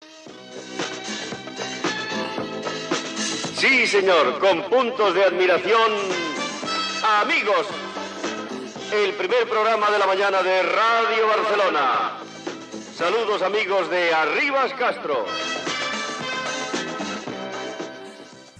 Sintonia i salutació inicial
Entreteniment